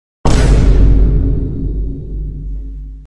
Sound Buttons: Sound Buttons View : Eyebrow Sfx
rock-eyebrow-sfx.mp3